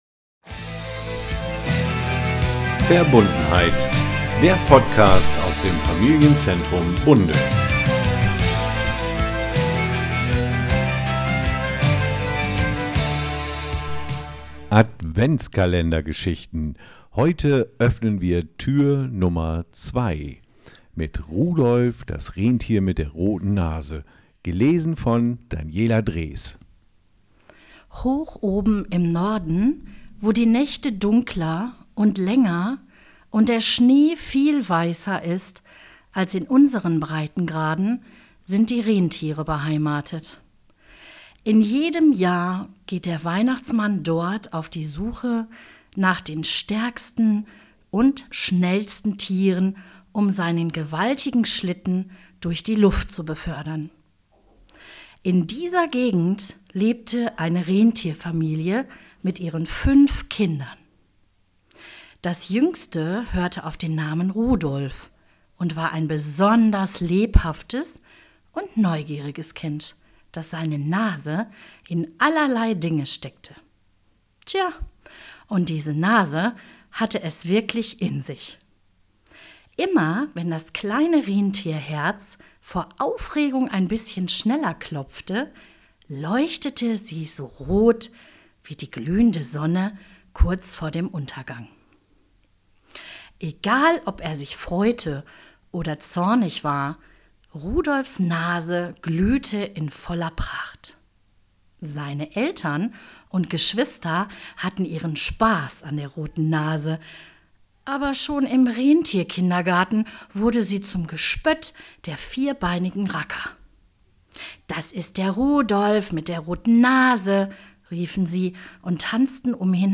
Rudolph, das Rentier mit der roten Nase - gelesen